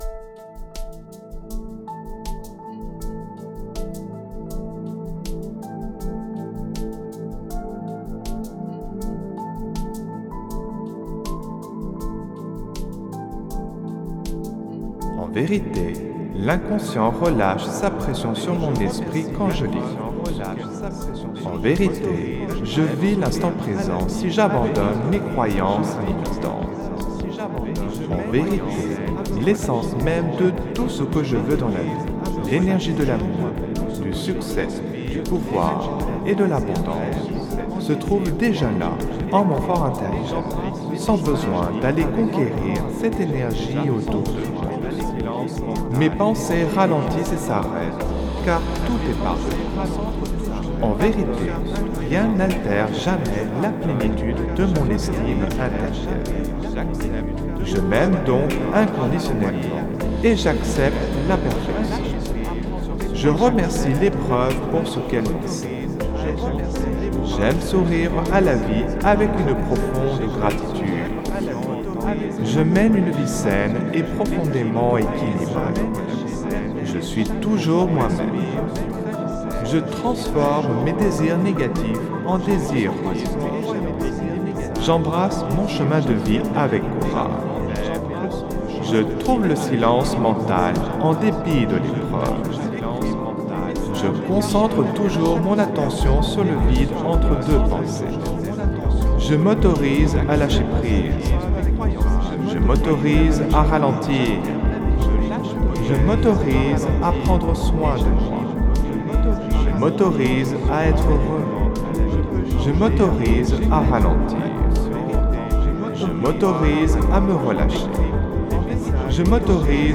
(Version ÉCHO-GUIDÉE)
LA QUINTESSENCE « tout-en-un » du développement personnel concentrée en un seul produit : Méditation, autosuggestion, message subliminal, musicothérapie, son binaural, fréquences sacrées, son isochrone, auto hypnose, introspection, programmation neurolinguistique, philosophie, spiritualité, musique subliminale et psychologie.
Alliage ingénieux de sons et fréquences curatives, très bénéfiques pour le cerveau.
Puissant effet 3D subliminal écho-guidé.
SAMPLE-Instant-present-2-echo.mp3